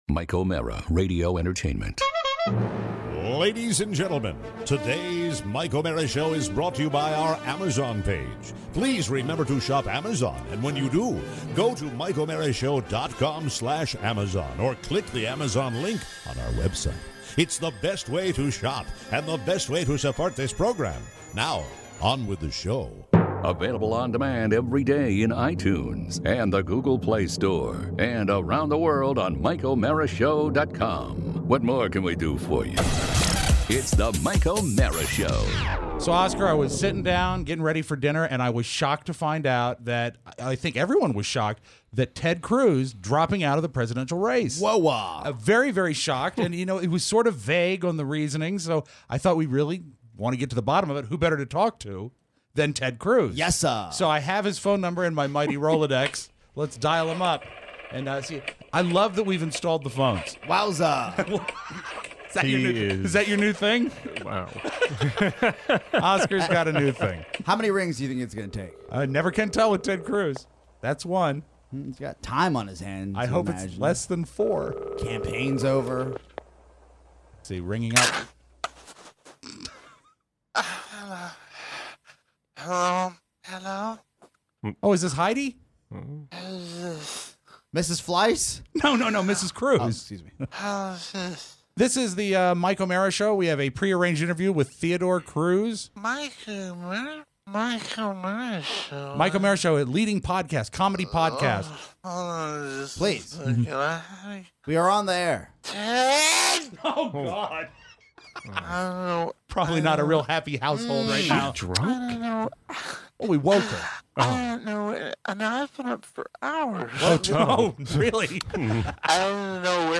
Today… “Ted Cruz” stops by… Plus, your live phone calls, rejection, a tale of a boat and the legend of BA.